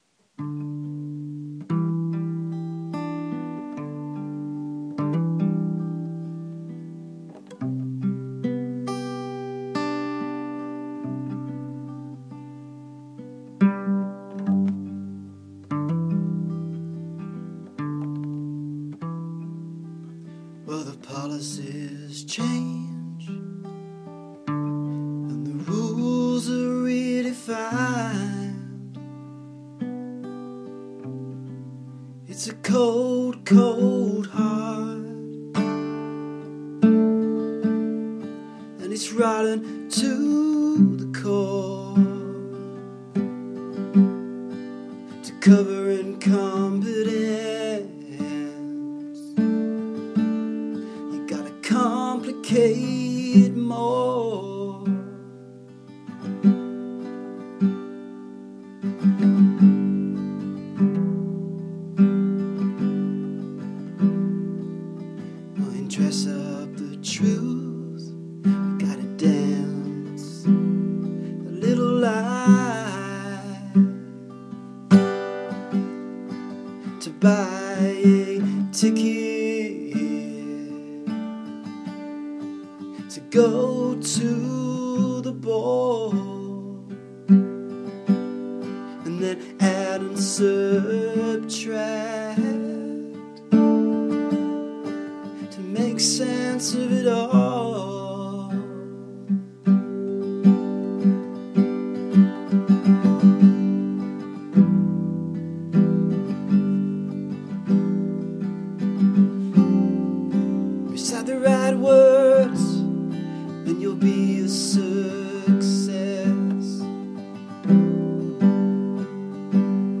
Rough recording of new song...All sense lost